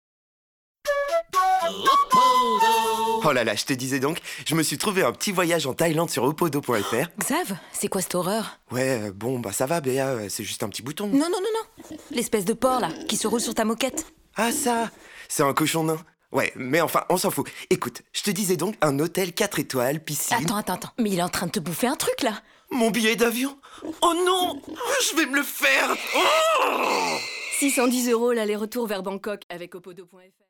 Pub DM - Voix jouée
2.Demo-Opodo-Pub-Voix-Jouee.mp3